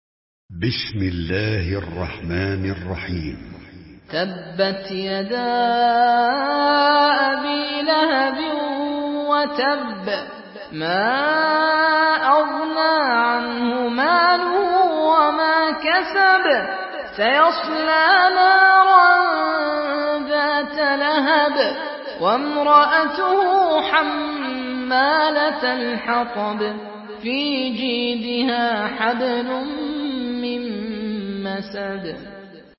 Une récitation touchante et belle des versets coraniques par la narration Hafs An Asim.
Murattal